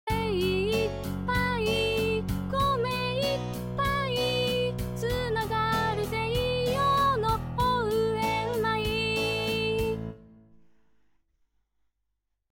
米いっぱい つながる西予の応援米」の歌詞を、市が指定するAI自動作曲システムを使って作曲。
• 西予市産米を活気づける明るく楽しいもの
• 老若男女わかりやすく、口ずさむことができ、親しみやすいもの